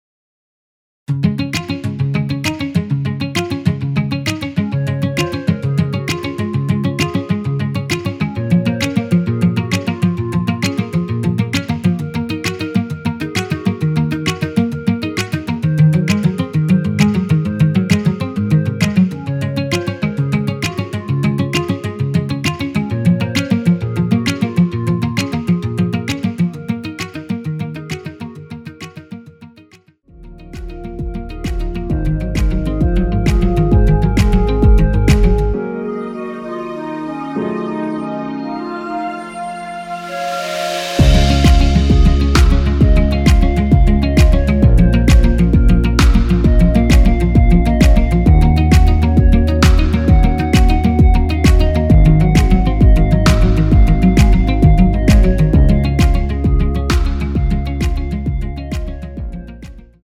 원키에서(-1)내린 멜로디 포함된 MR입니다.(미리듣기 확인)
Db
앞부분30초, 뒷부분30초씩 편집해서 올려 드리고 있습니다.
중간에 음이 끈어지고 다시 나오는 이유는